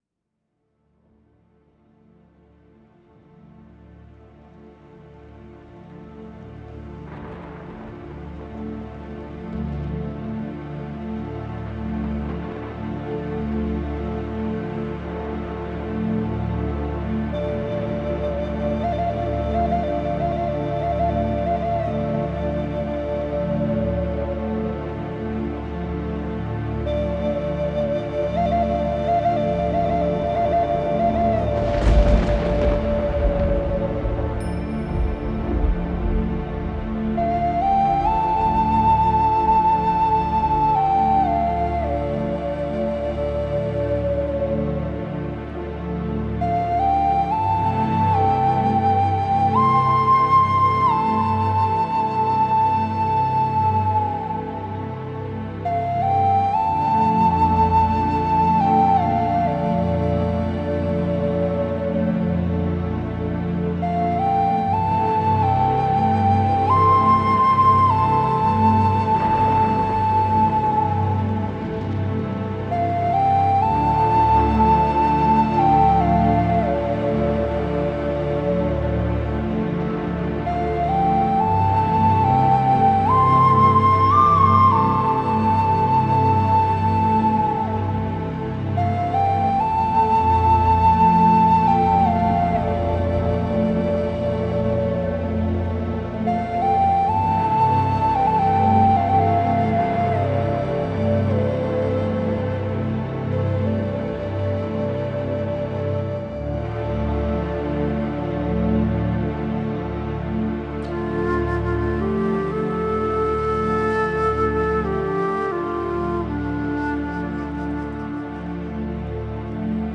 live instrumental album
GK Guitar
Harmonicas
Flute
Percussion